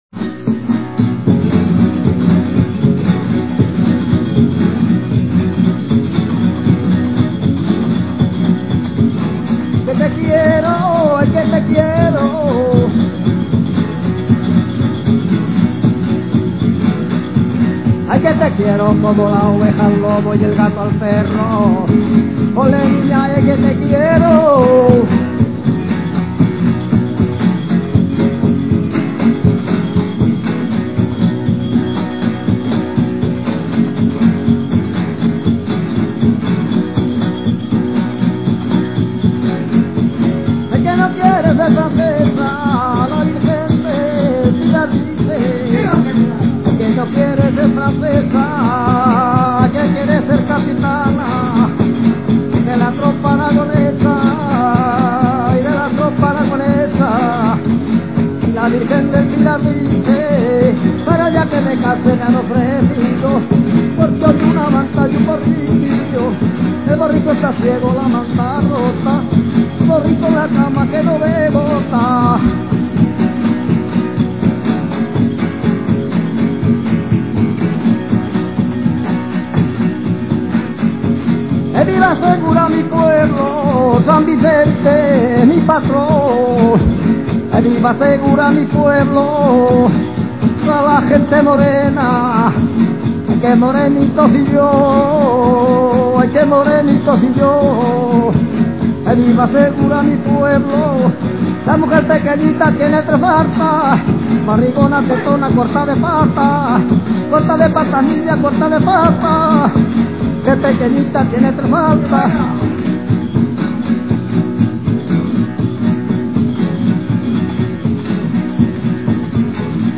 Todas estas piezas se tocan con instrumentos de cuerda como la guitarra, la bandurria o el laúd, acompañados siempre del tintinear de los platillos. Estas coplas son sinfonías del pueblo, sus letras son graciosas, irónicas y algo picantillas en algunas ocasiones, letras que presentan el carácter de la gente de Segura.
Estas piezas están extraídas de una grabación de 1982-83 e interpretadas por
voz y guitarra
platillos
bandurria